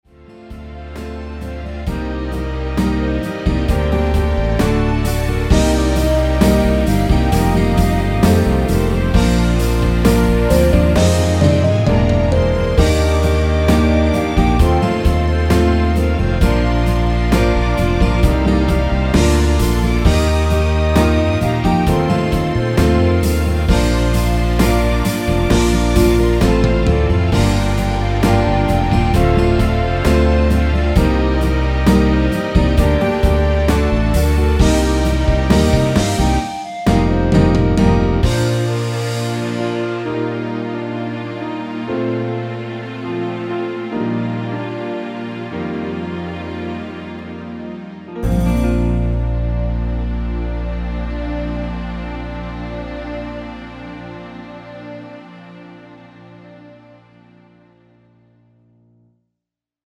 원키 멜로디 포함된 (짧은편곡) MR입니다.
Ab
앞부분30초, 뒷부분30초씩 편집해서 올려 드리고 있습니다.
중간에 음이 끈어지고 다시 나오는 이유는